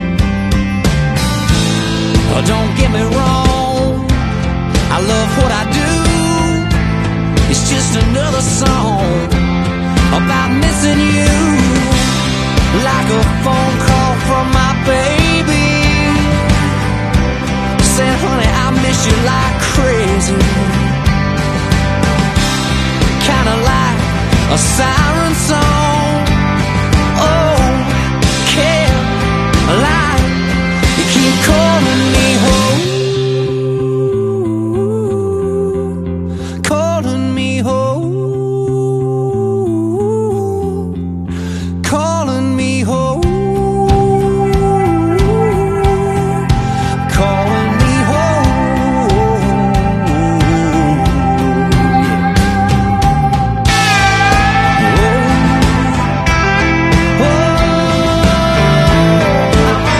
Michael Jordan & Eric Church watching Bill Belichick’s first game with the North Carolina Tar Heels vs. the TCU Horned Frogs in Kenan Stadium